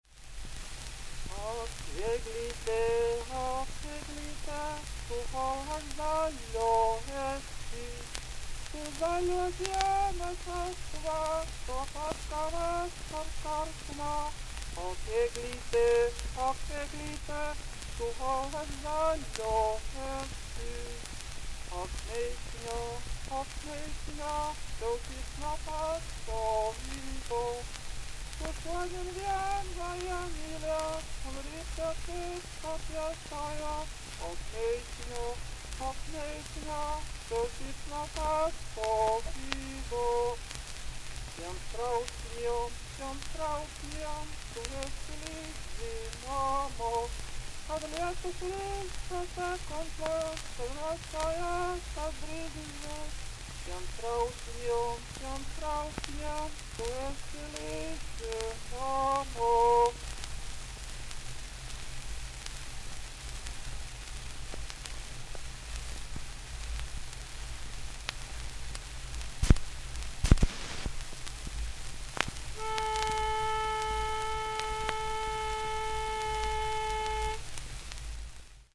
Eglite, Gesang“; Schellack, Humboldt-Universität zu Berlin, Musikwissenschaftliches Seminar, Lautarchiv, Inv.-Nr. PK 126.
Die hier vorliegende Aufnahme wurde 1916 unter dem Titel "Eglite" durch einen Internierten des Russischen Reichs in dem Lager Merseburg auf Lettisch angestimmt.
Alle Schellackplatten der Kommission weisen am Ende den mit einer Stimmpfeife eingespielten Kammerton auf, der zu der Zeit noch für A = 435 Hz betrug.
Zwischen der dritten Strophe und dem Stimmton ist ein lautes Knacken zu hören.